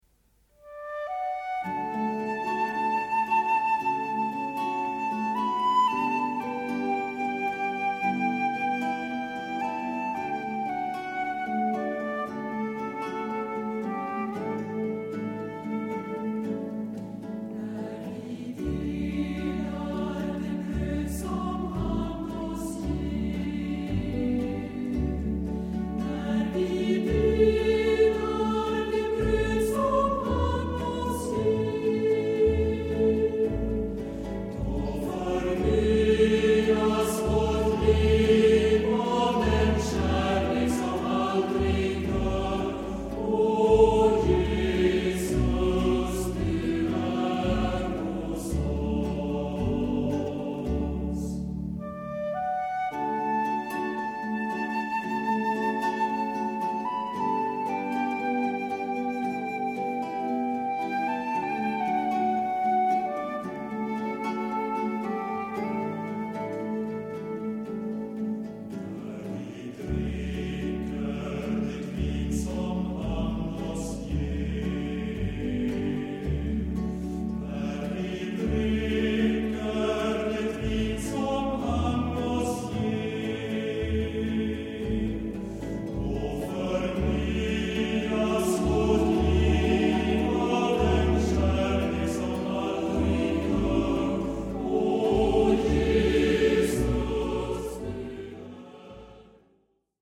★ 安定祥和、莊嚴聖潔的人聲合唱讓您暫離塵囂，百聽不厭！
★ 北國瑞典天使歌聲，獨特純淨空靈意境、音色柔軟綿密、通透清晰！